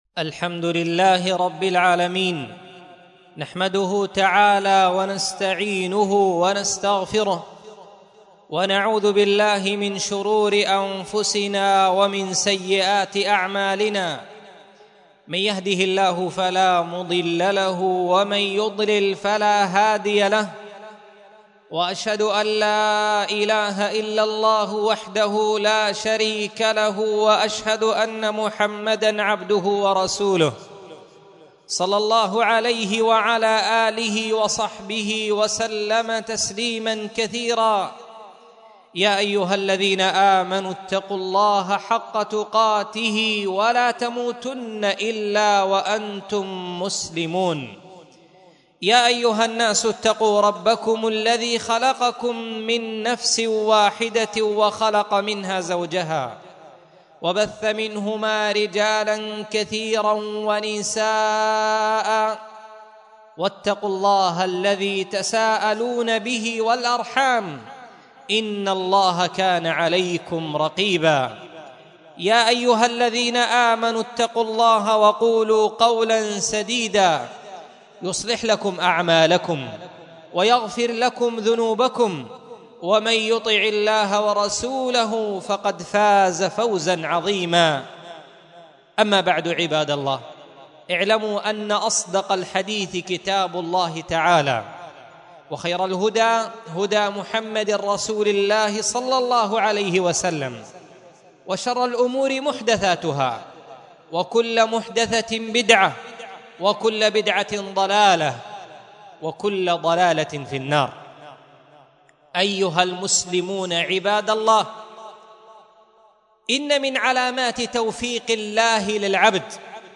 مسجد درة عدن محافظة عدن حرسها الله